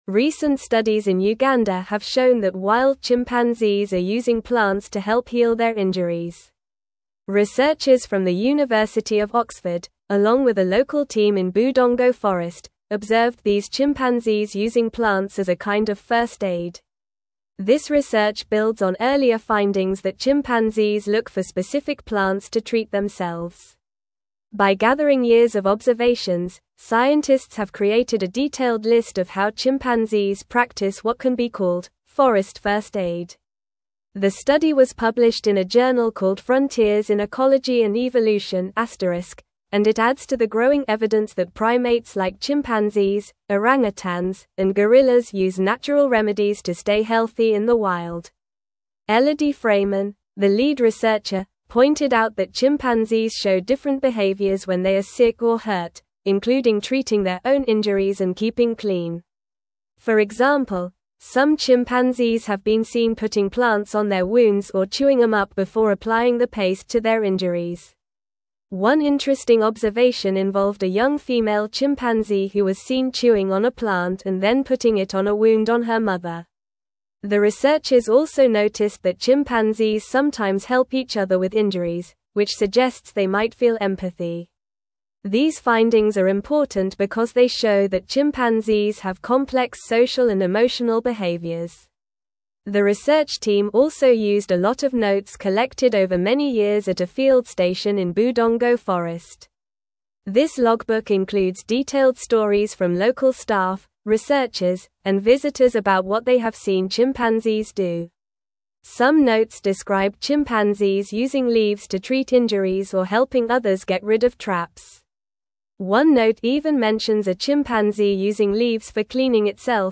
Normal
English-Newsroom-Upper-Intermediate-NORMAL-Reading-Chimpanzees-Use-Medicinal-Plants-for-Self-Care-in-Uganda.mp3